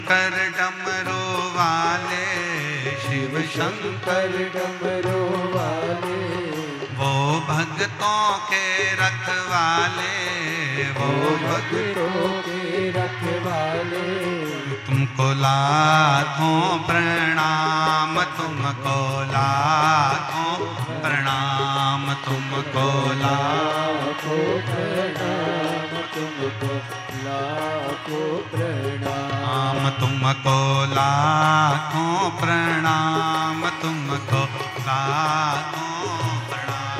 शिव भजन रिंगटोन